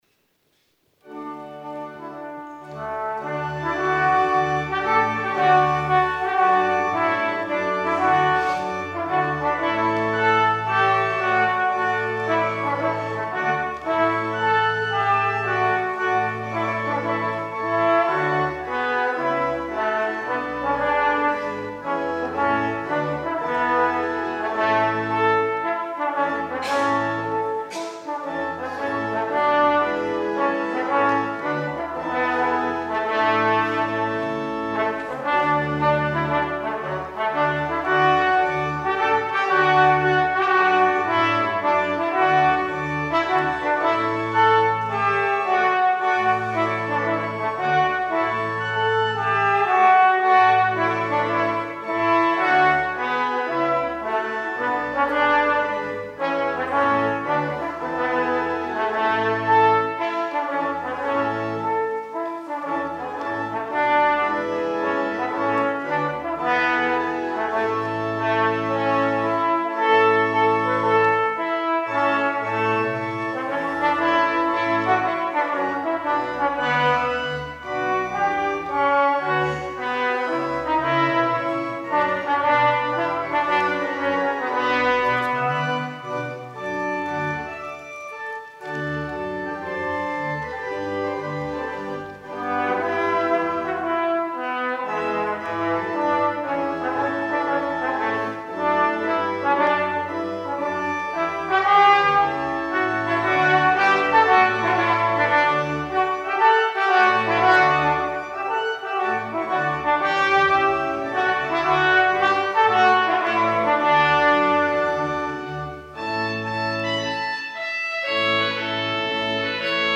trombone
organ